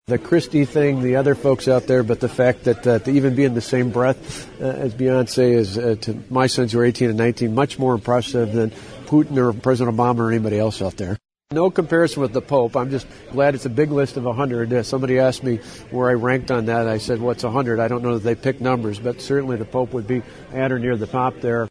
Governor Scott Walker spoke to reporters in the Appleton area Thursday, after the announcement of his inclusion of Time magazine's list of the 100 most influential people.